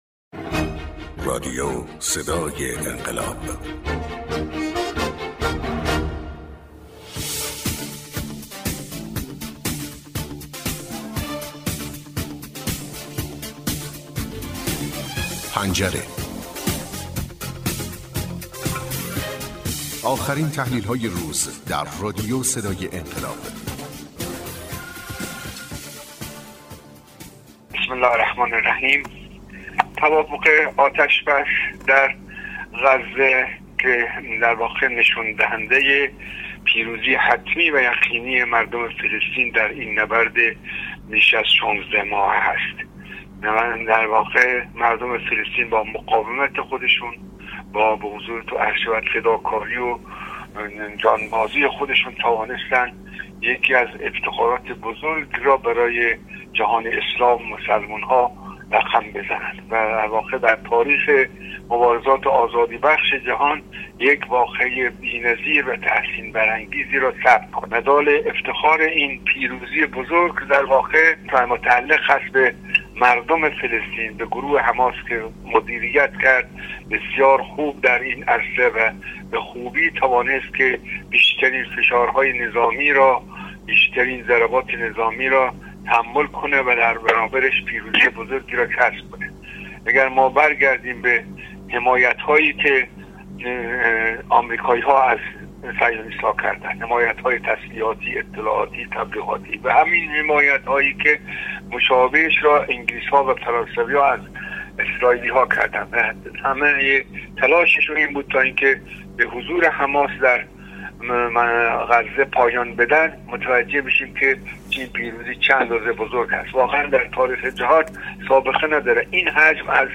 کارشناس برنامه: